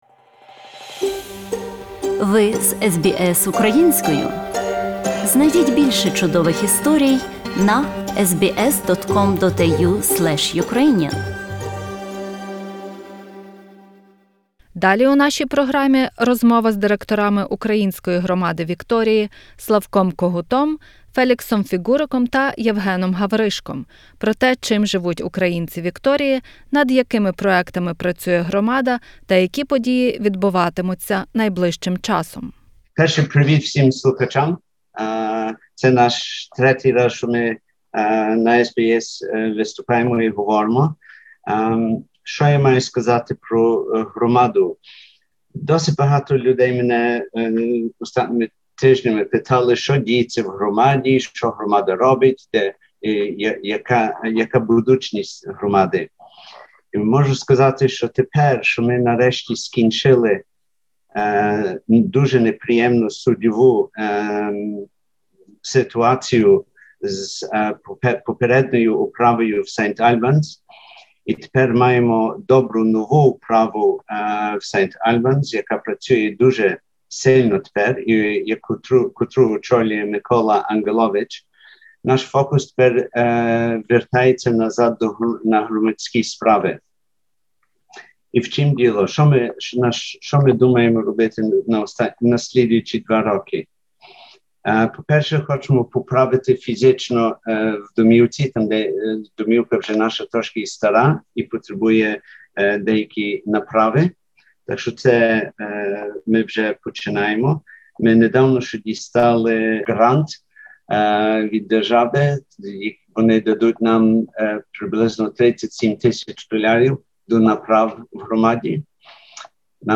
Громадська розмова